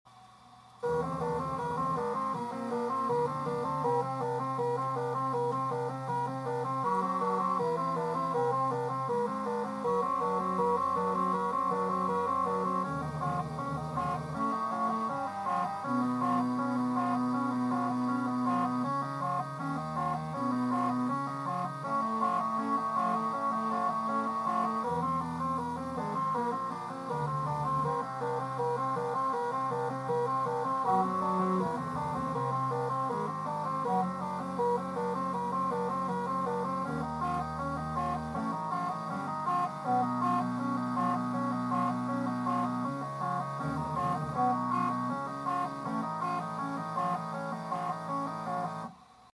and it sounds pretty evil